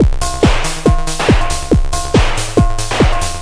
这是对循环的一种变体。
奇怪的低音（或旋律）是对调制器进行过多调整的结果。